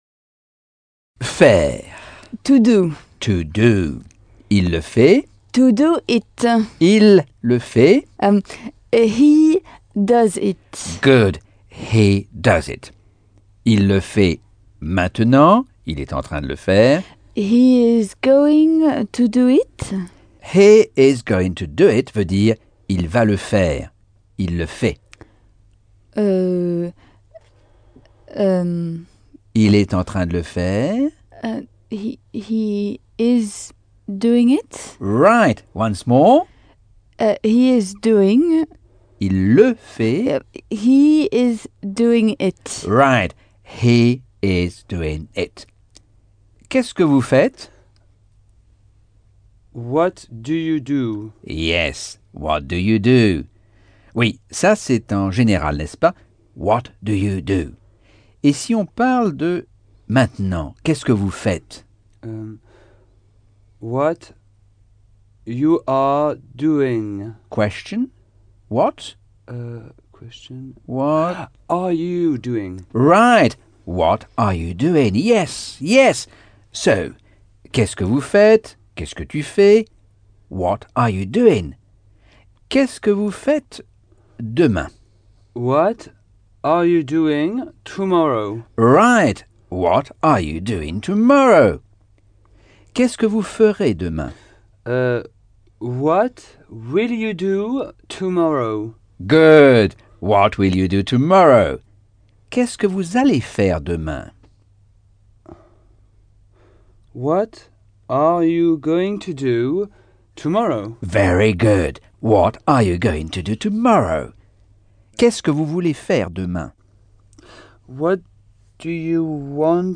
Leçon 1 - Cours audio Anglais par Michel Thomas - Chapitre 7